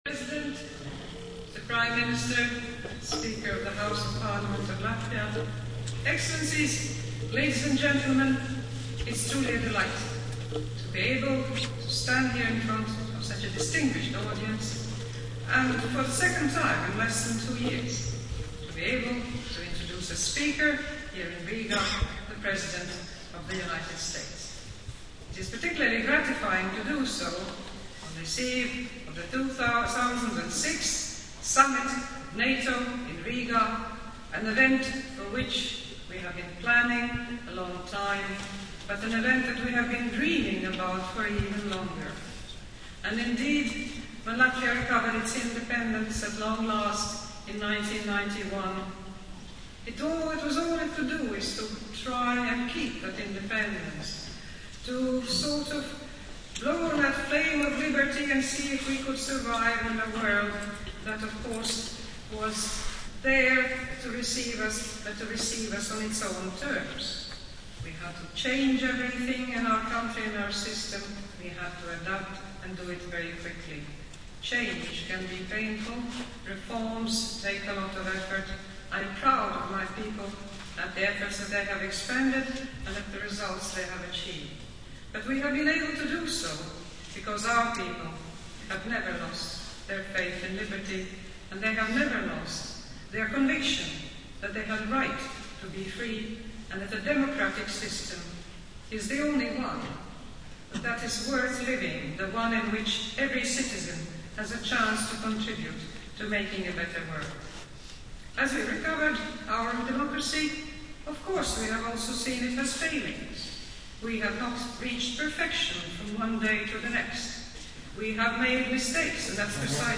Valsts prezidentes ievadvārdi ASV prezidenta Džordža Buša runai 2006. gada 28. novembrī Latvijas Universitātes Lielajā aulā (+audio) | Latvijas Valsts prezidenta mājaslapa
Valsts prezidente piesaka ASV prezidentu LU